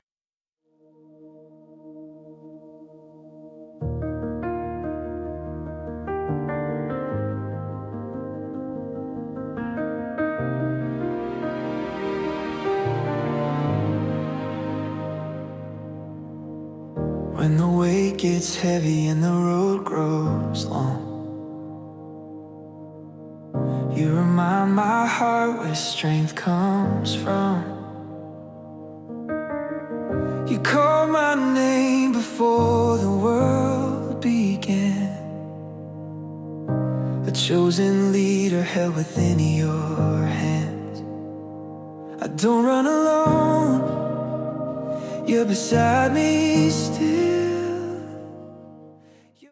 🎧 Original Worship Song + Lyrics (MP3) for prayerful focus